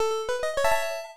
LevelUp1.wav